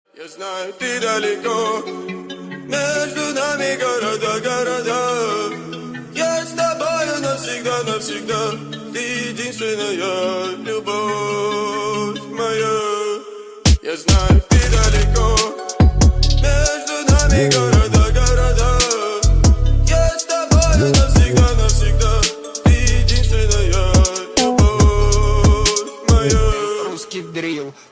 • Качество: 128, Stereo
мужской голос
басы
Cover
качающие